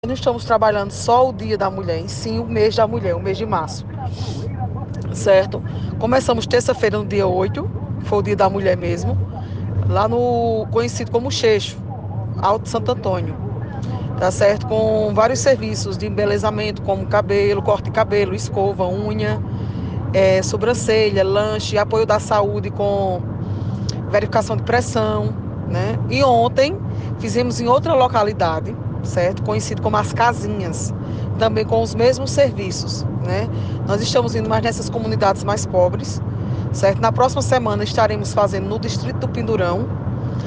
A secretária de Assistência Social de Camalaú, Iara Mariano, concedeu uma entrevista na última sexta-feira (11), em que abordou sobre as ações da secretaria voltadas para as mulheres neste mês de março.